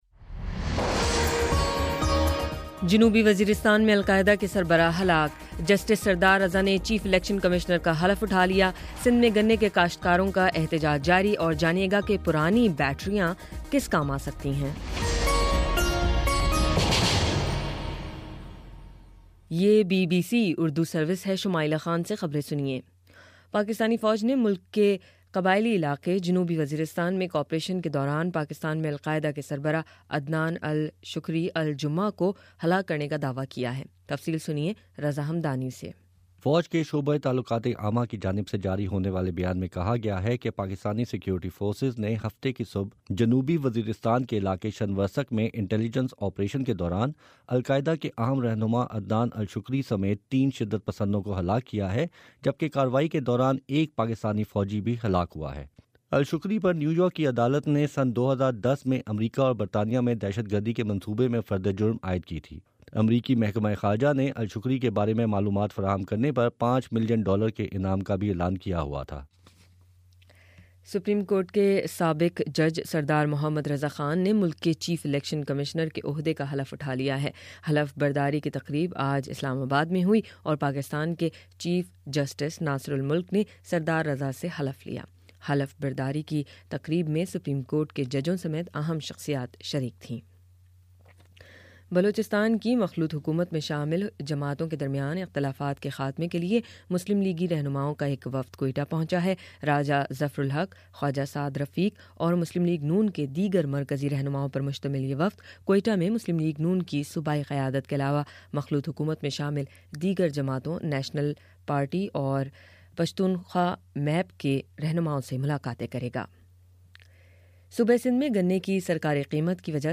دسمبر 06 : شام سات بجے کا نیوز بُلیٹن